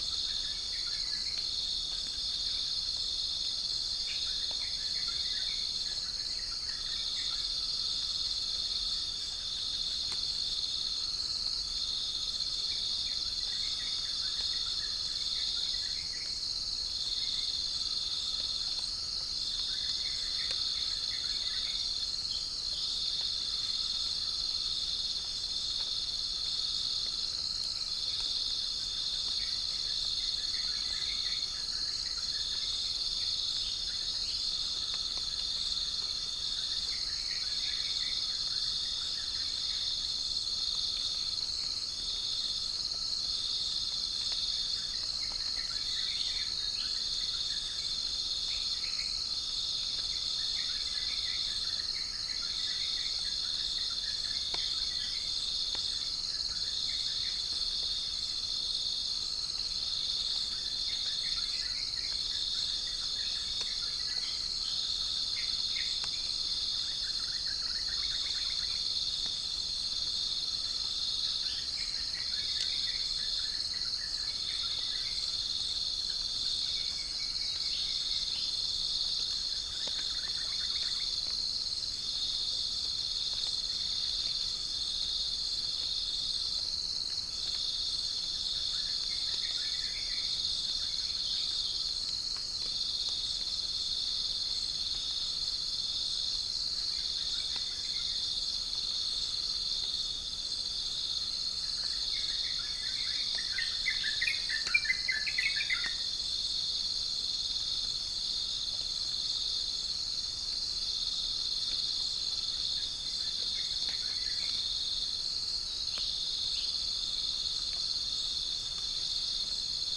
Spilopelia chinensis
Pycnonotus goiavier
Rhipidura javanica
Prinia familiaris